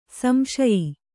♪ samśayi